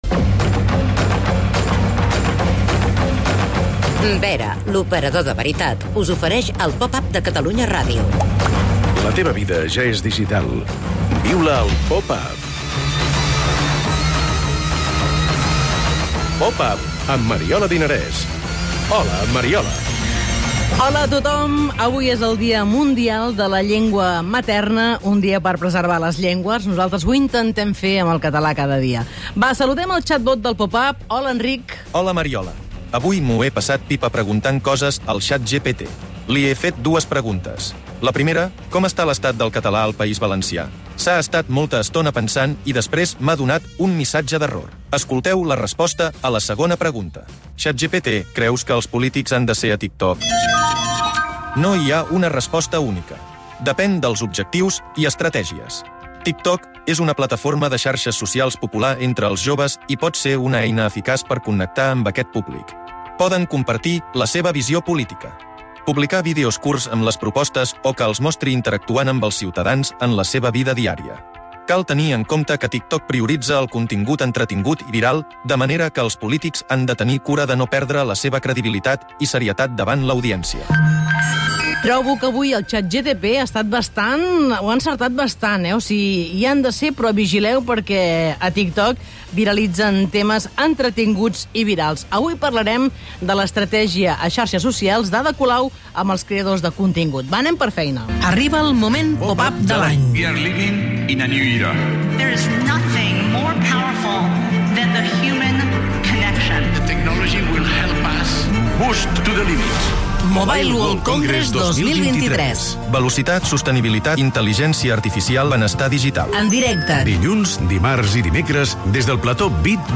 entrevisten el creador de contingut